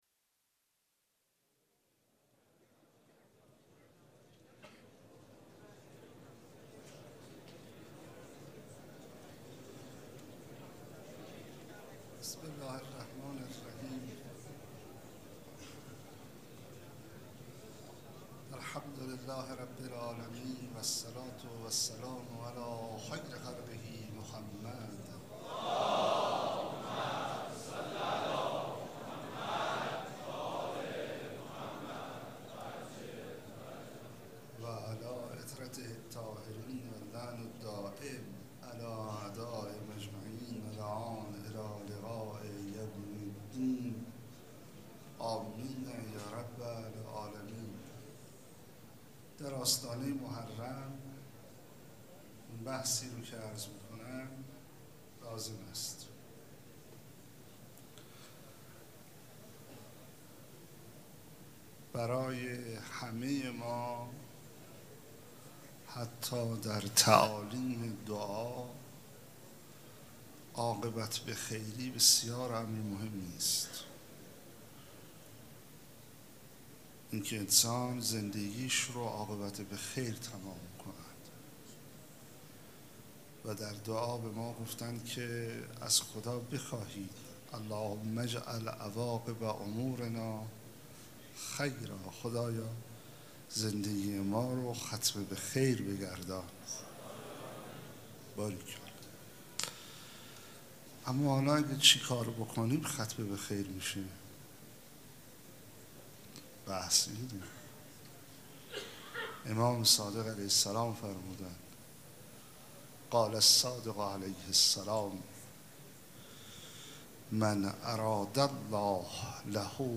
سخنرانی
مـراسـم سیاه پوشان دوشنبه ۲۷ شهریور ماه ١٣٩۶
سخنرانی.mp3